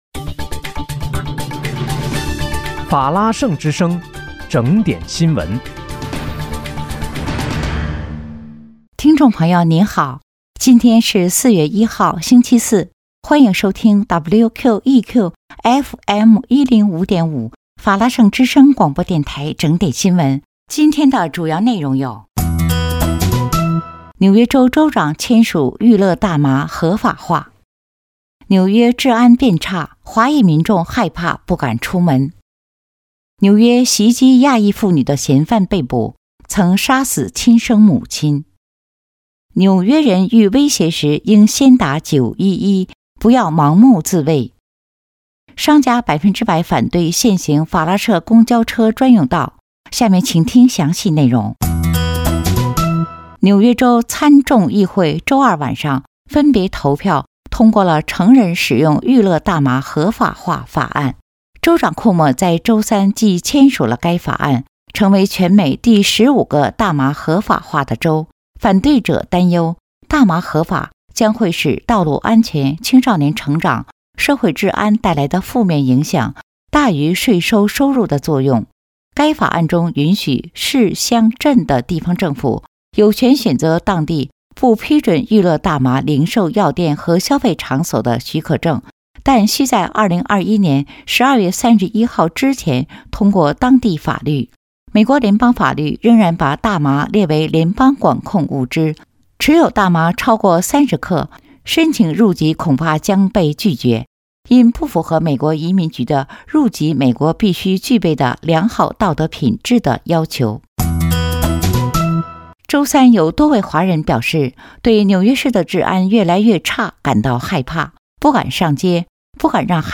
4月1日（星期四）纽约整点新闻